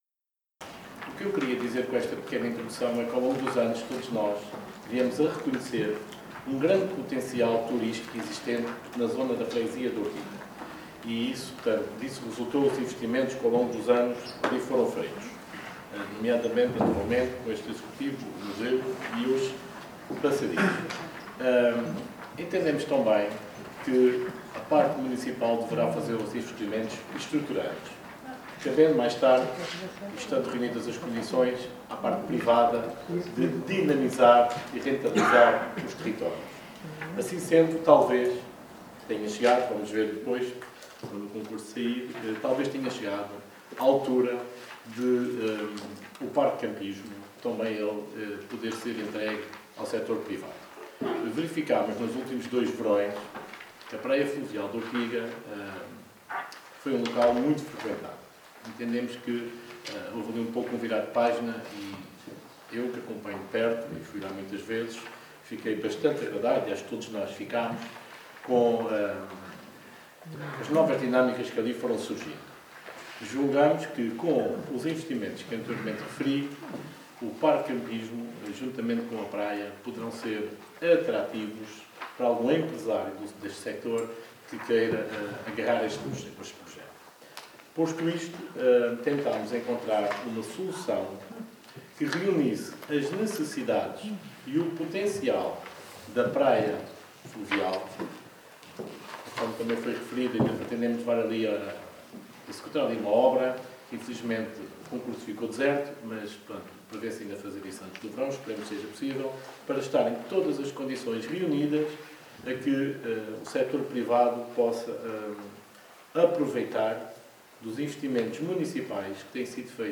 ÁUDIO | Vasco Marques, vereador da Câmara Municipal de Mação